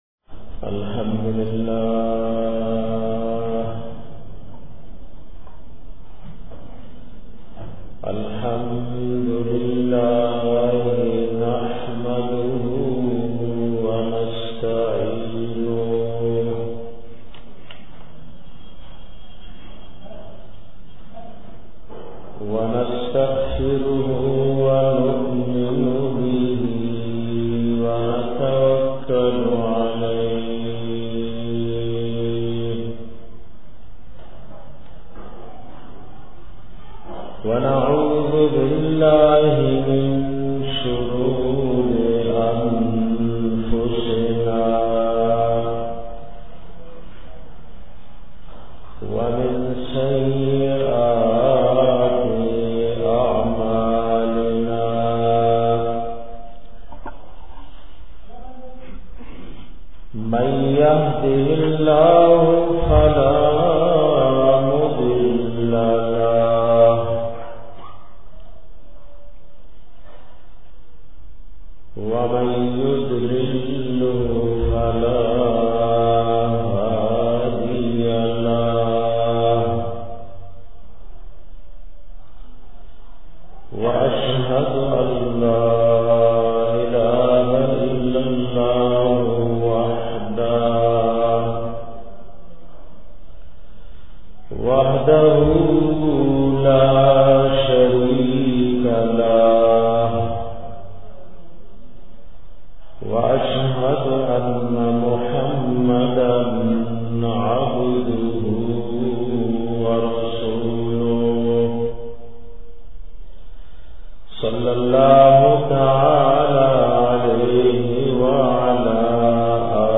bayan da sheethan na da bachao asbab 4